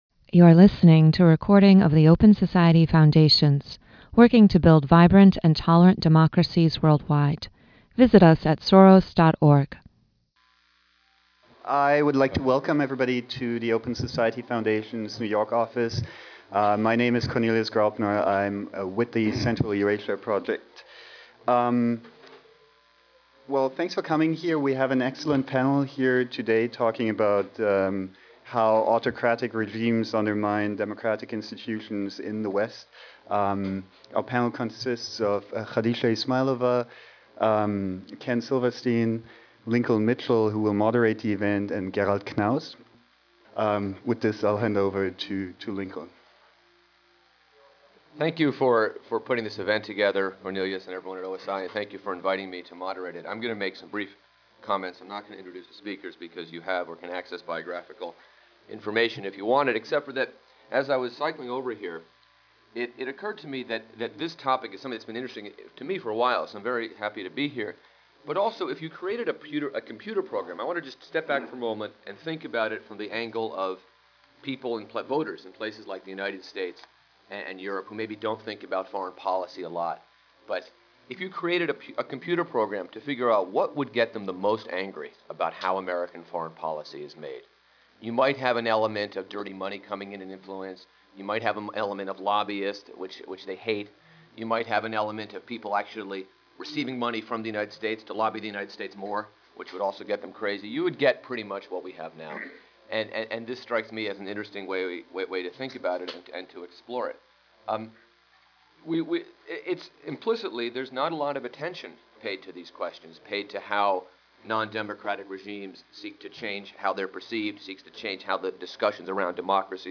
This panel discussion examines how nondemocratic regimes in the former Soviet Union have increased their efforts to influence policymakers and public opinion in the United States and the European Union.